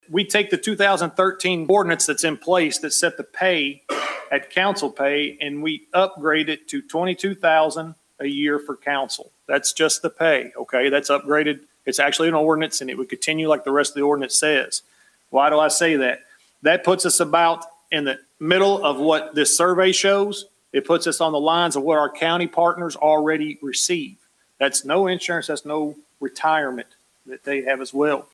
During the Committee of the Whole meeting Thursday night, Councilman Clayton Sumner motioned to increase council compensation.